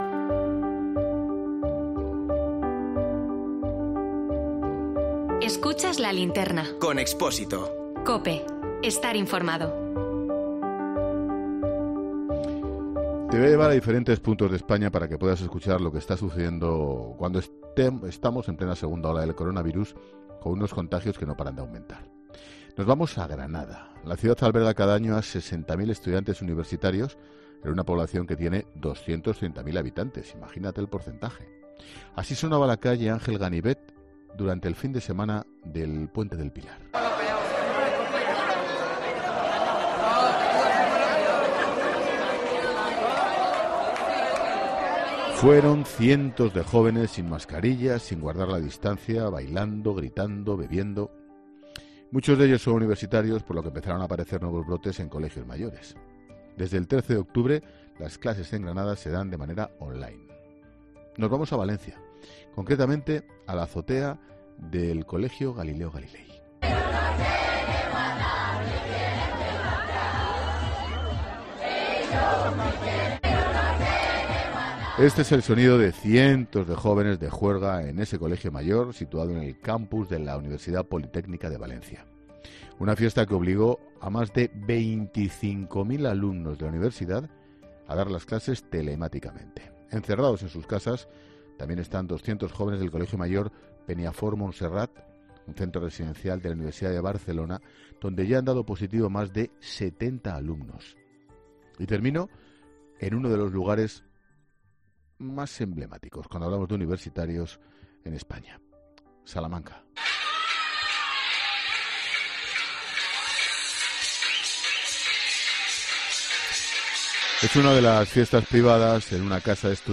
Así sonaba la calle Ángel Ganivet durante el fin de semana del puente del Pilar .Son cientos de jóvenes sin mascarilla y sin guardar la distancia social bailando, gritando y bebiendo.